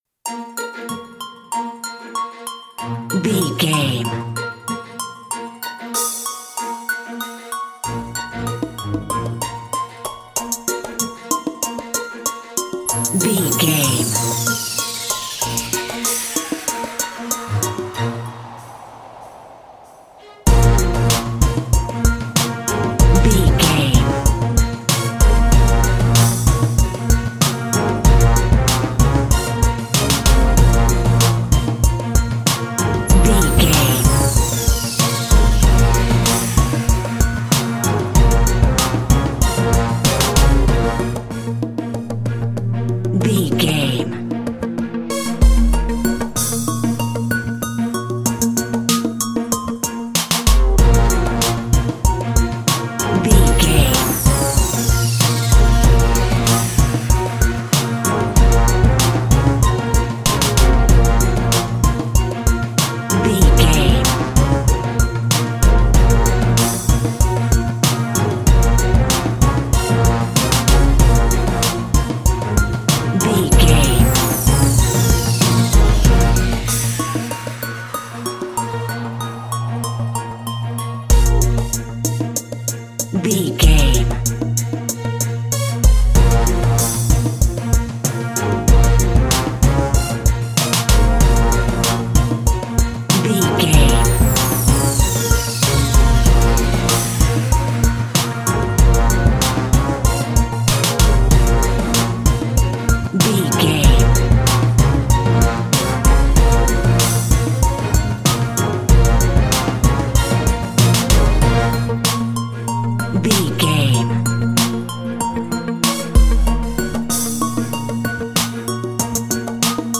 Aeolian/Minor
B♭
drums
percussion
strings
conga
brass
funky
hard hitting